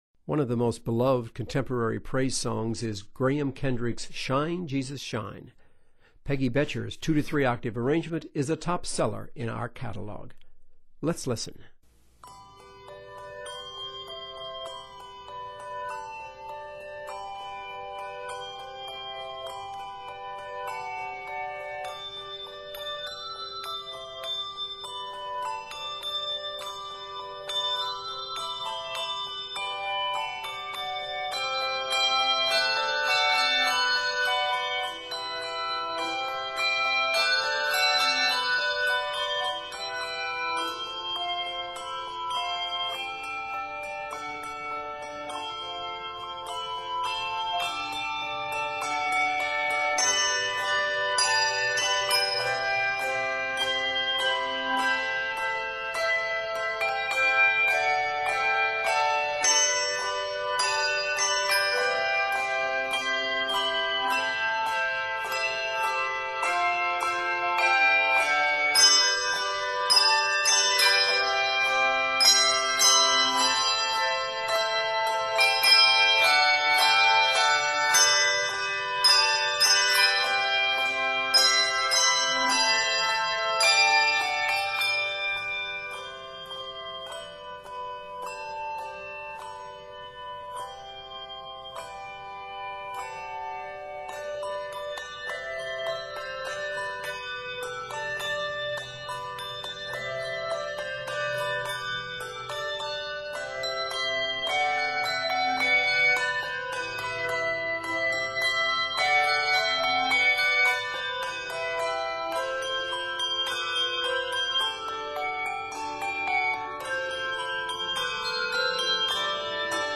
praise song
It is scored in G Major and is 68 measures.